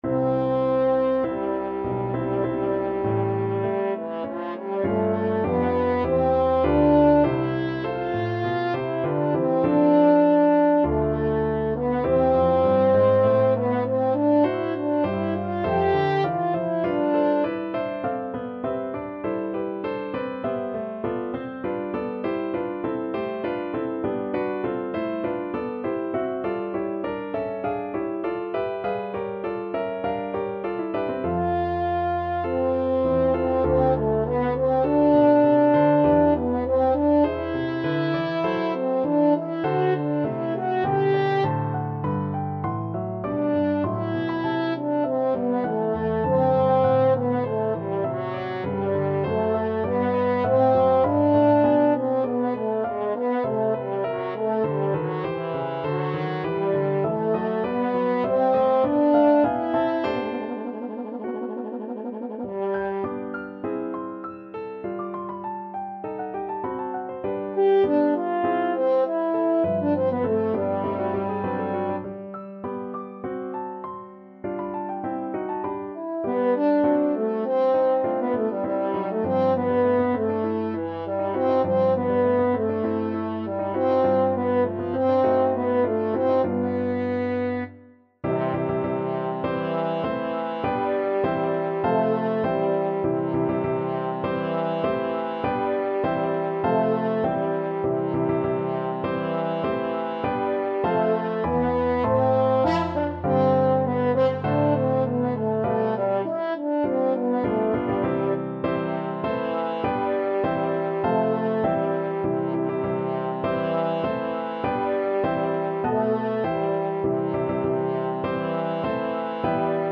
French Horn
C major (Sounding Pitch) G major (French Horn in F) (View more C major Music for French Horn )
Molto moderato mosso
4/4 (View more 4/4 Music)
Classical (View more Classical French Horn Music)